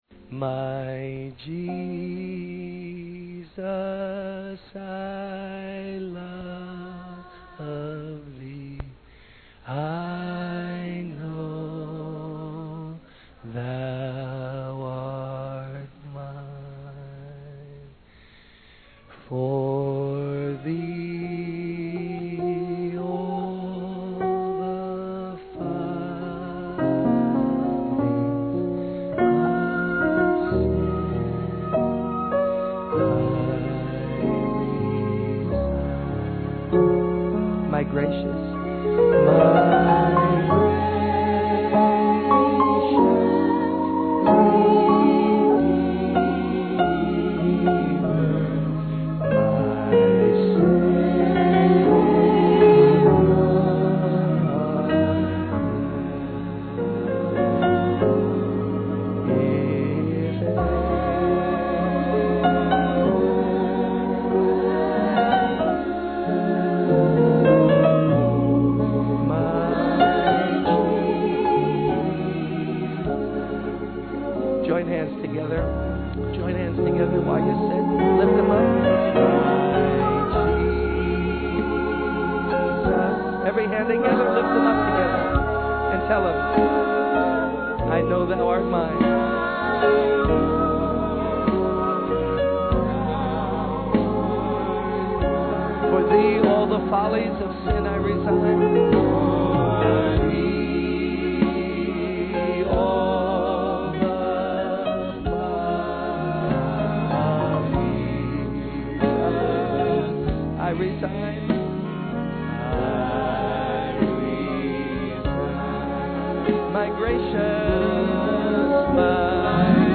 My Jesus, I Love Thee (Brookyln Tabernacle Service)